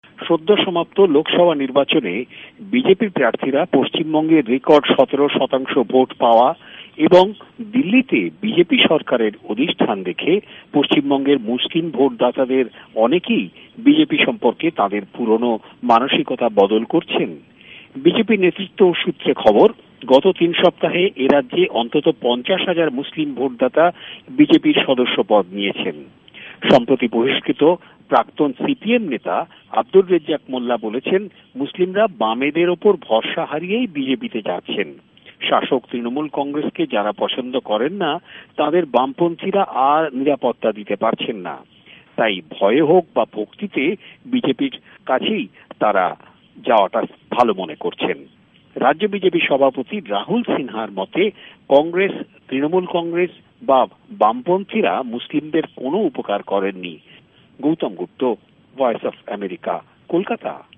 ভয়েস অফ এ্যামেরিকার কলকাতা সংবাদদাতাদের রিপোর্ট